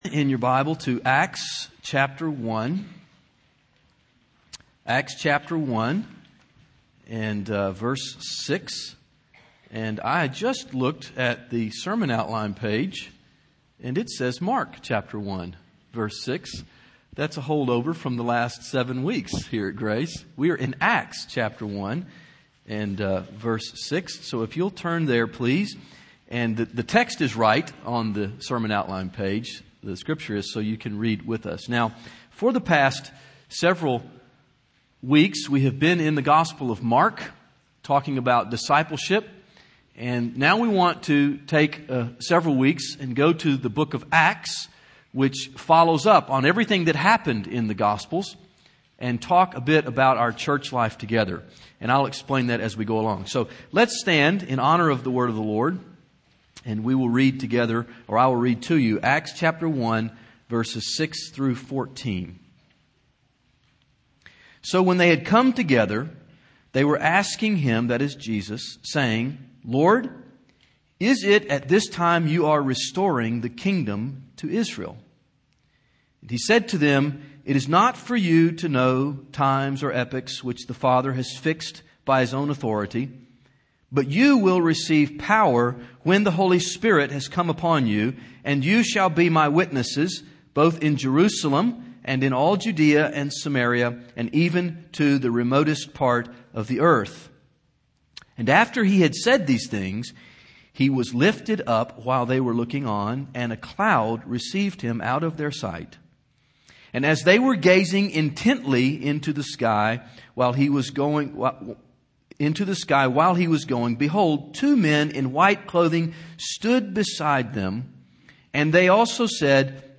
Grace Community Church Nashville | While We Wait: What the Church Is To Do Until Jesus Returns